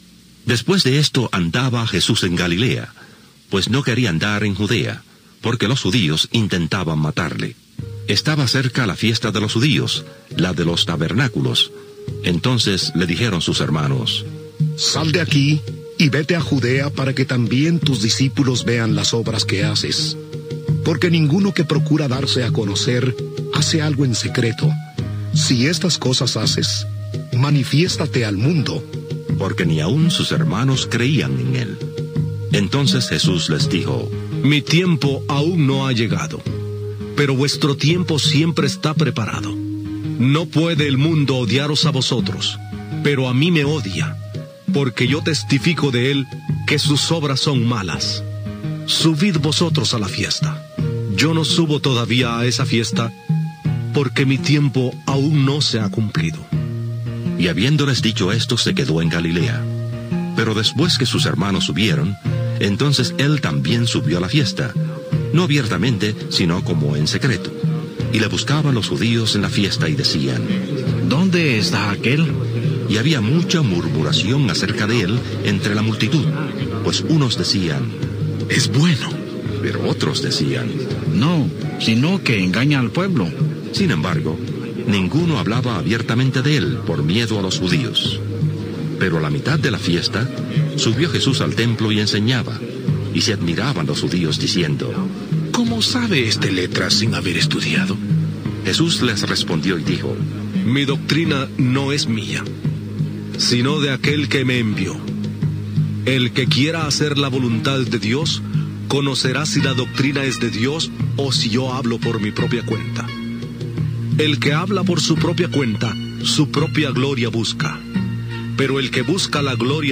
Fragment dramatitzat de la Bíblia, identificació, adreça de contacte, hi ha un judici després de la mort?